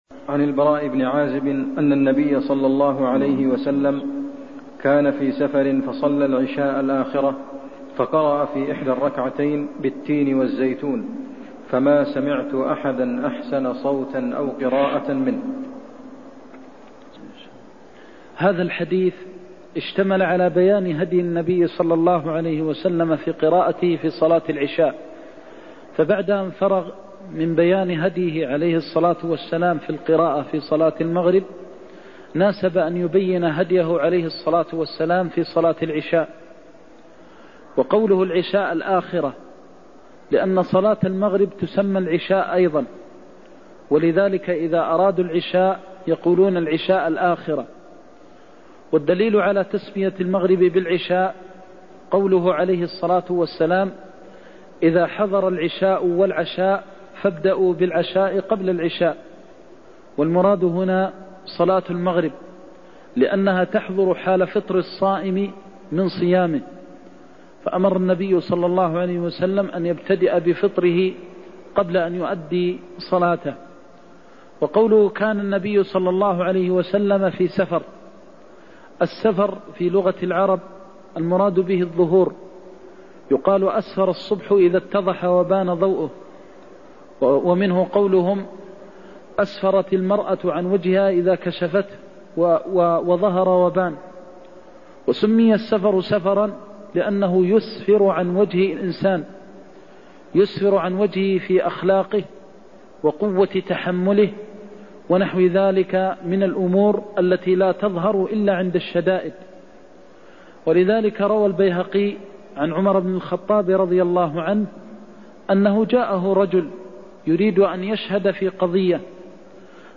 المكان: المسجد النبوي الشيخ: فضيلة الشيخ د. محمد بن محمد المختار فضيلة الشيخ د. محمد بن محمد المختار قرأ في أحد الركعتين بالتين والزيتون (96)